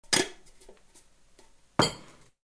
Звуки посуды
Крышку кастрюли надевают, ставят на стол